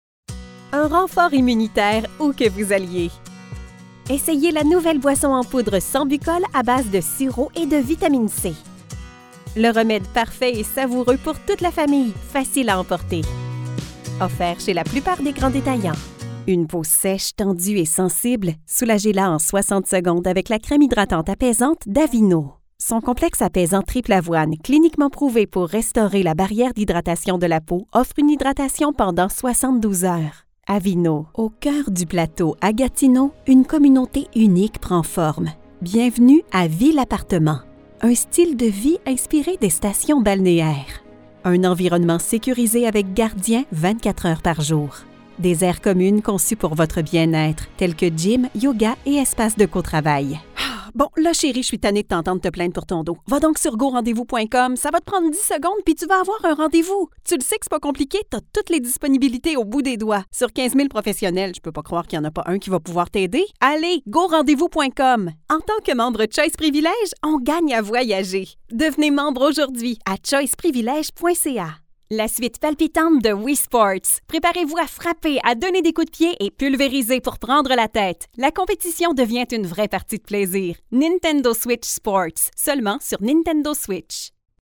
French Canadian Commercial Demo
French Canadian, Quebec French, neutral French
Young Adult
Middle Aged